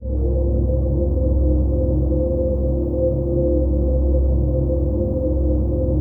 ATMOPAD08.wav